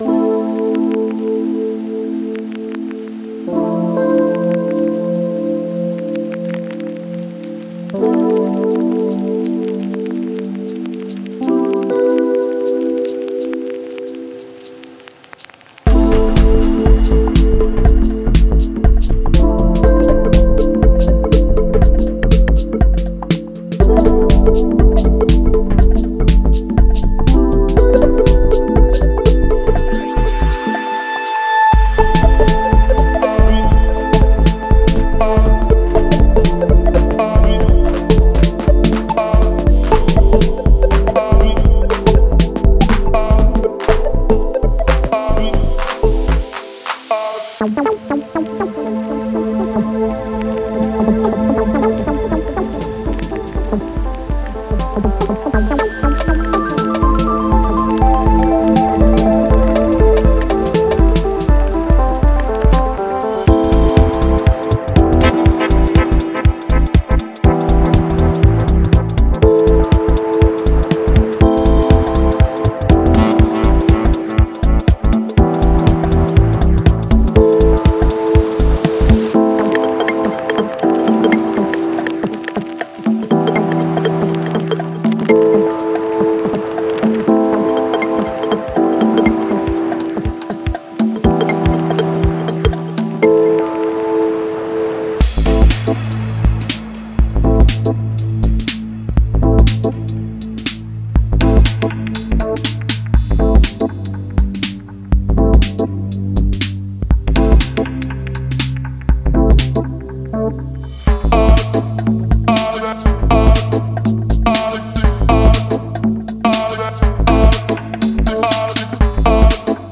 Genre:House
落ち着いたグルーヴ、穏やかなシンセ、そして温かみのあるバイブスを厳選したセレクションです。
デモサウンドはコチラ↓